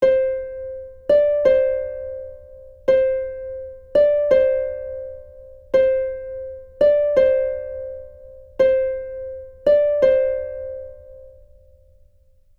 The third line of Betty Lou has a new rhythm: a dotted quarter note followed by an eighth note. Here's how this combination sounds (audio repeats four times):